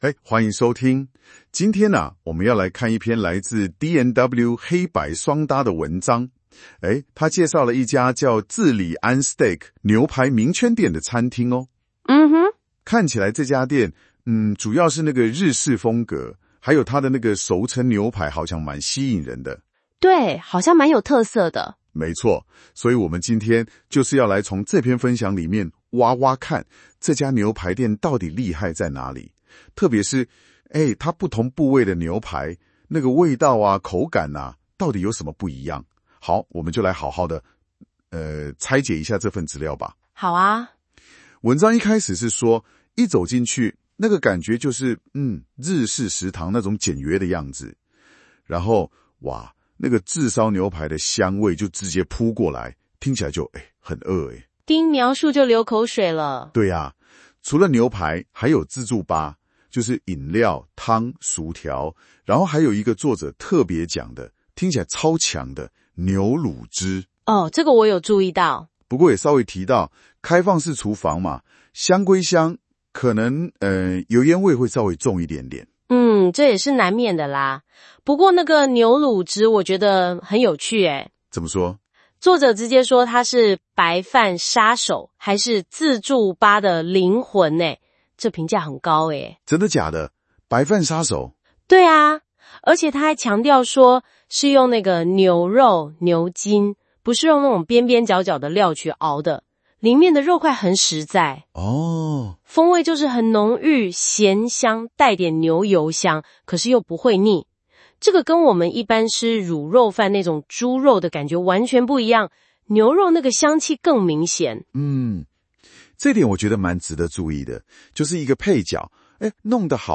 新功能!現在用【說】的方式介紹文章哦!
我們請兩位主持人專業講解，介紹D&W黑白雙搭本文章